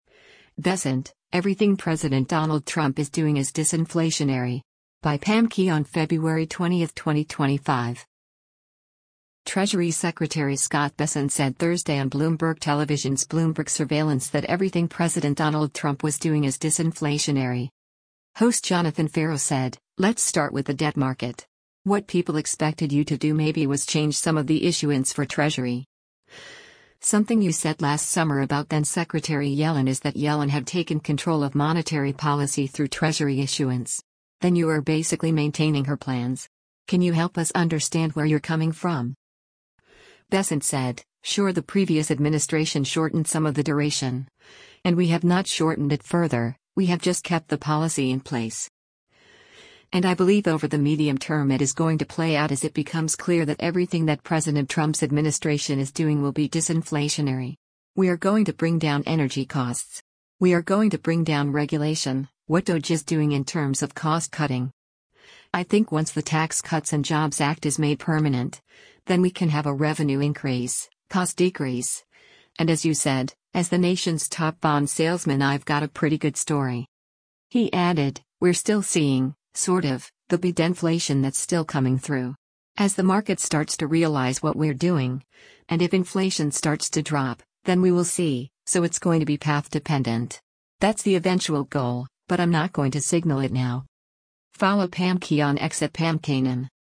Treasury Secretary Scott Bessent said Thursday on Bloomberg Television’s “Bloomberg Surveillance” that everything President Donald Trump was doing is “disinflationary.”